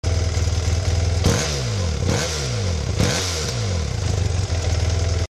quand je vous disais que la F800 fait un son de merde :/
+1 y'en a une qui a demarré devant moins l'autre jour à la residence  :sweat:
Qui à lancer le debrouissailleur  [:fatal]